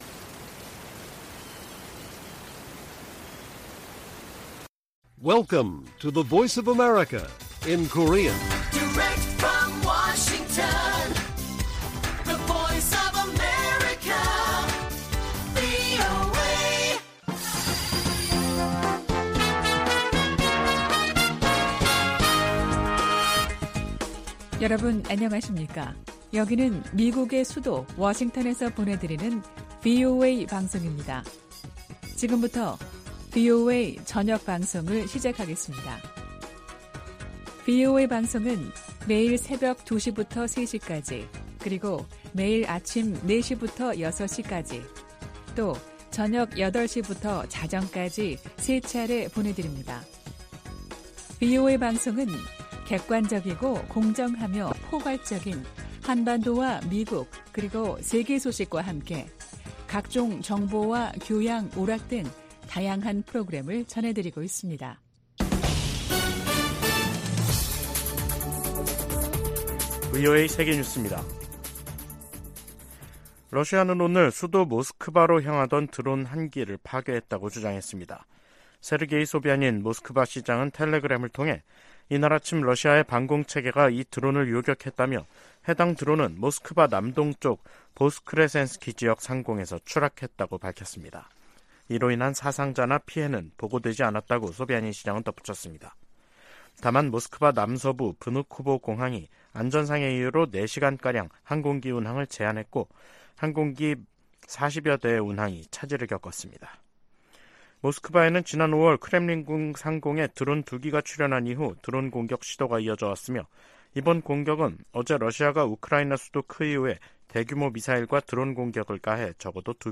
VOA 한국어 간판 뉴스 프로그램 '뉴스 투데이', 2023년 8월 31일 1부 방송입니다. 북한이 미한 연합훈련에 반발해 동해상으로 탄도미사일을 발사 했습니다.